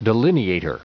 Prononciation du mot delineator en anglais (fichier audio)
delineator.wav